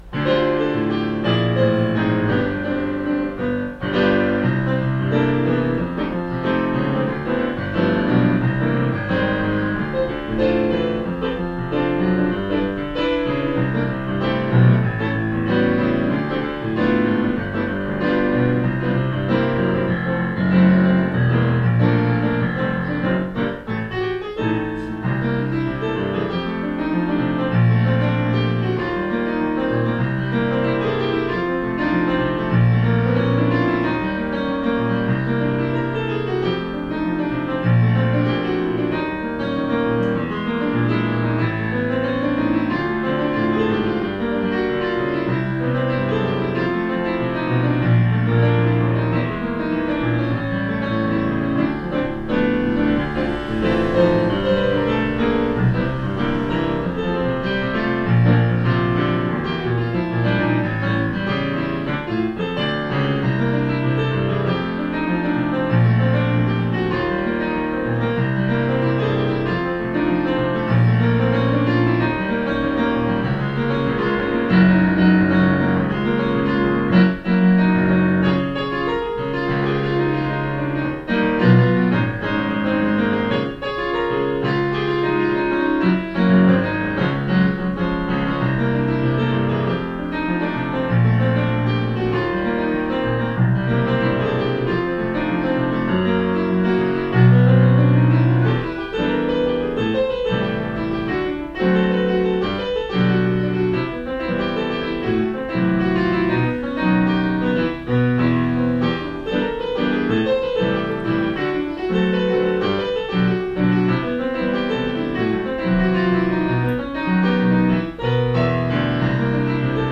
(למי שמבין קצת במוזיקה.. הקטע נקרא כך משום שהוא מבוסס בעיקר על אקורדים שמורכבים מ9 ו13)
ד' בתמוז תשע"ה, 18:26 הנגינה מהממת, ההקלטה גרועה...